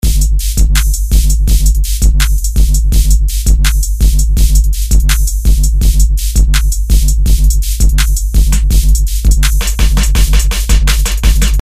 Tag: 83 bpm Rap Loops Drum Loops 1.95 MB wav Key : Unknown